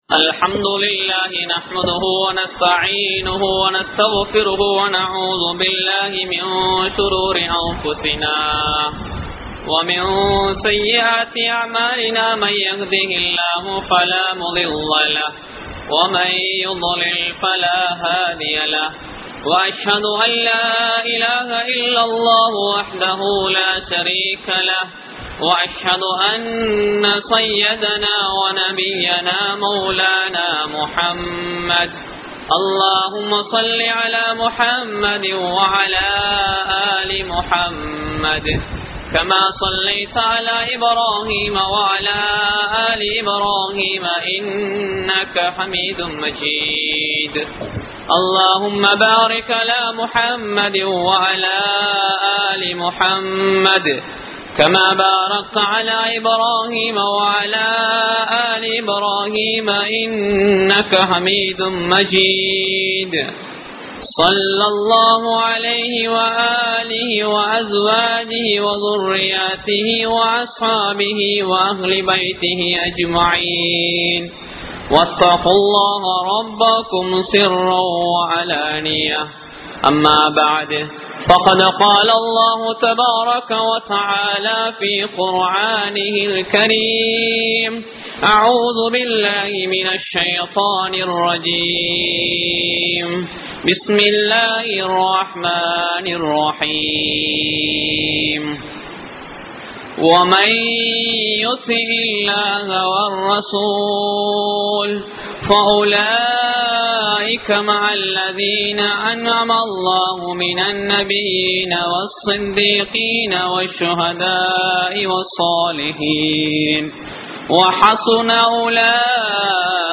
Perunaal Thina Olukkangal (பெருநாள் தின ஒழுக்கங்கள்) | Audio Bayans | All Ceylon Muslim Youth Community | Addalaichenai
Jumua Masjidh